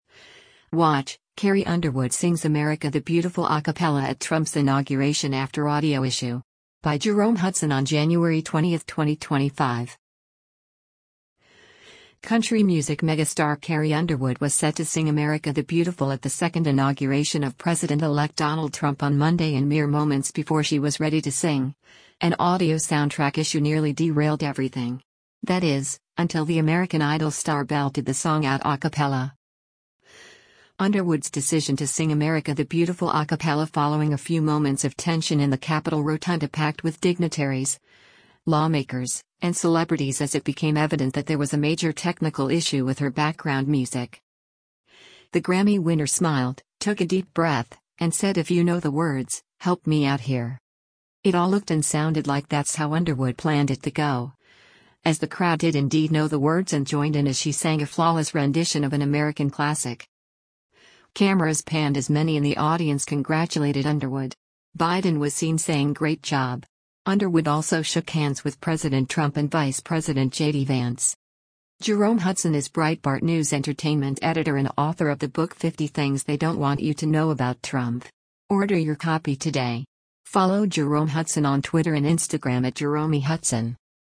Watch: Carrie Underwood Sings 'America the Beautiful' a Cappella at Trump's Inauguration After Audio Issue
Underwood’s decision to sing “America the Beautiful” a capella following a few moments of tension in the Capitol Rotunda packed with dignitaries, lawmakers, and celebrities as it became evident that there was a major technical issue with her background music.
It all looked and sounded like that’s how Underwood planned it to go, as the crowd did indeed know the words and joined in as she sang a flawless rendition of an American classic.